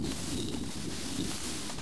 eagle_fly.wav